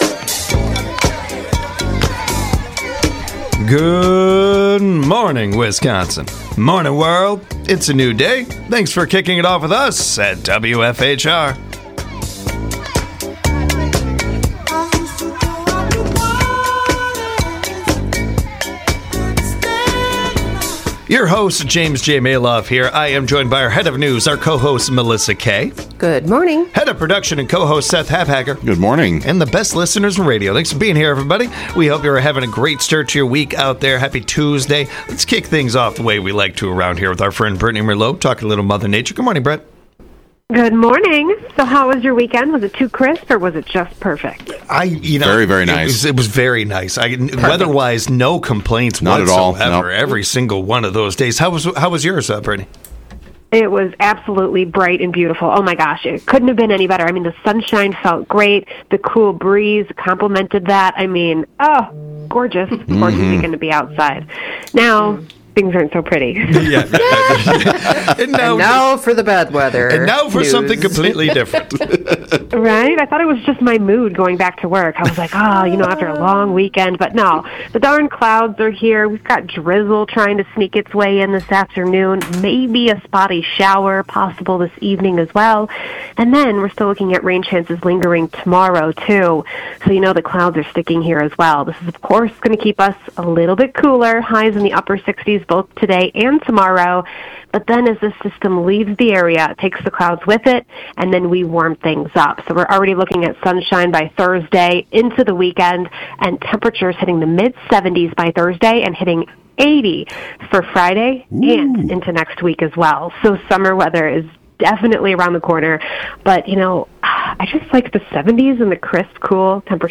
The trio celebrate your birthdays and anniversaries with El Café. National Sunscreen day, and some sunscreen stories. Vacation stats and figures.